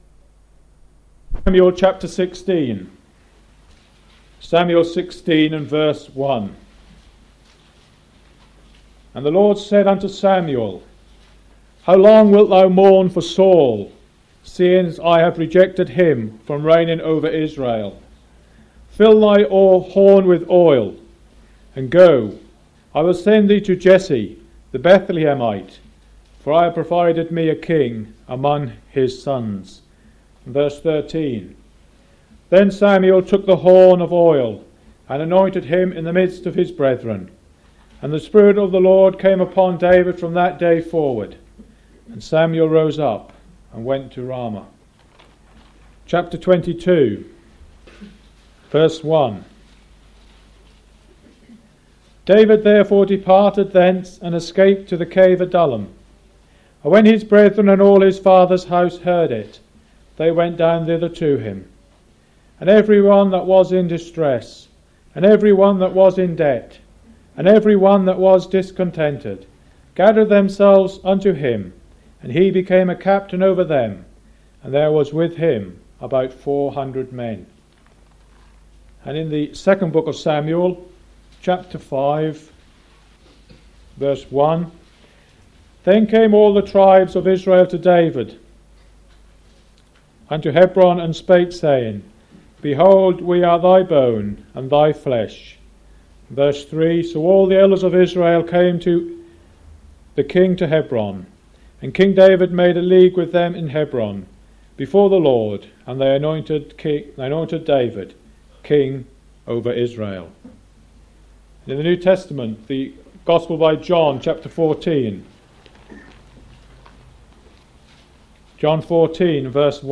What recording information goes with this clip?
View our Christian Ministry downloadable mp3 recordings from lectures and conferences across the UK over the last 60 years.